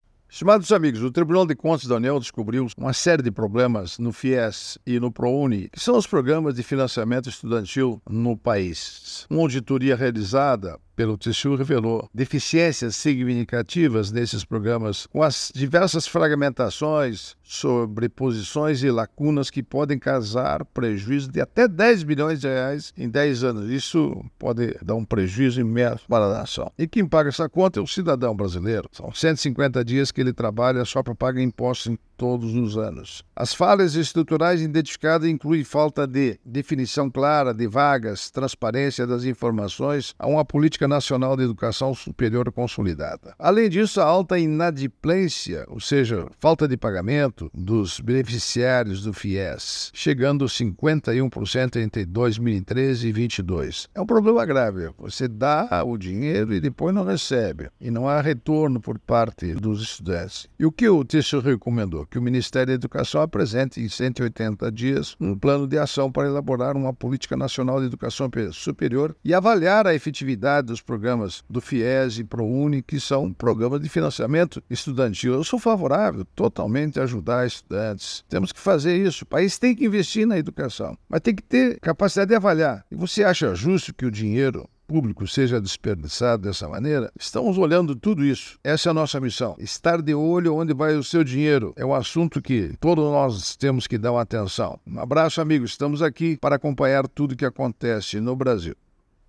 São os assuntos do comentário desta quarta–feira (18/09/24) do ministro Augusto Nardes (TCU), especialmente para OgazeteitO.